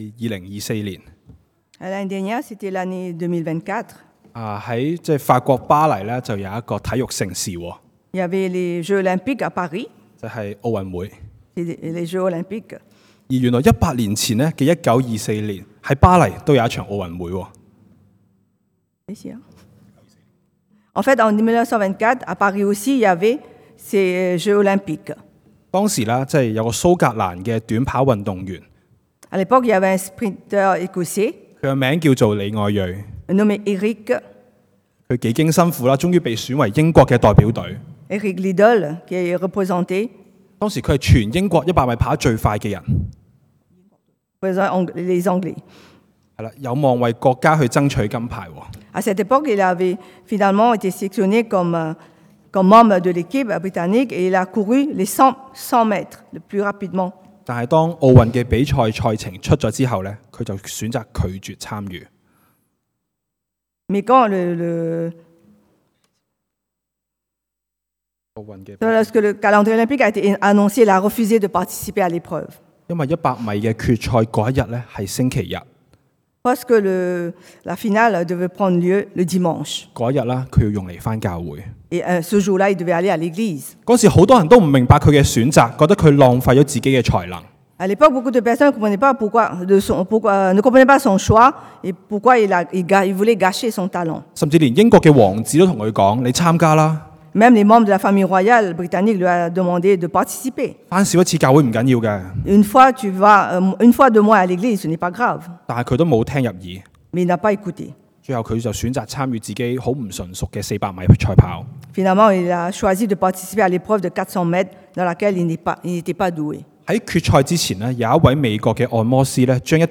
Type De Service: Predication du dimanche